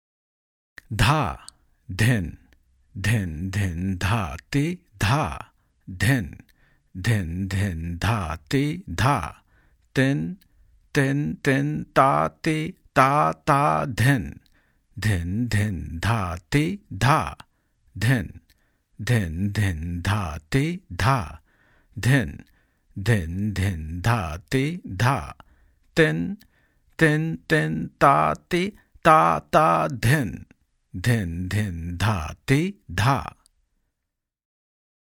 In this section and the next, there are practice variations, accompaniment variations, and theka improvisations in vilambit and madhyalay tempos (slow and medium):
• Practice variations introduce simple theka embellishments and fills (kism), but which are repetitive (usually the same embellishment repeats in each divisions of the tal).